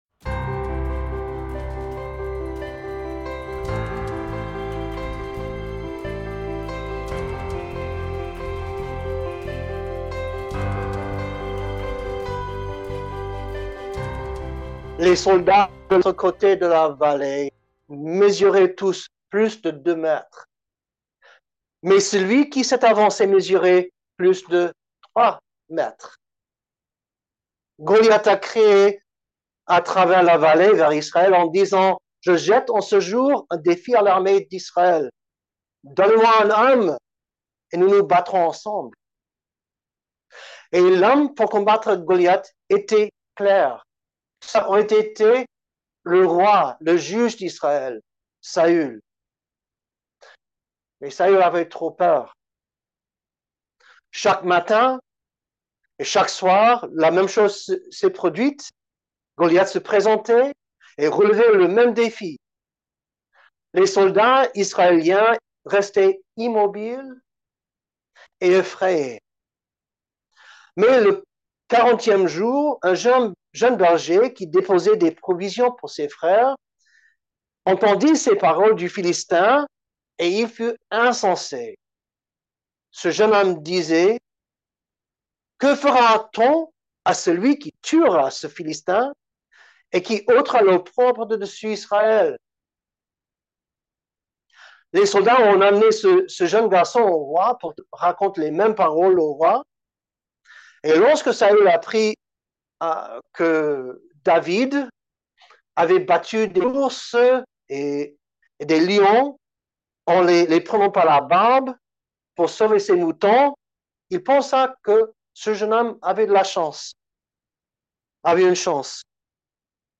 Given in Bordeaux